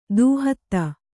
♪ dūhatta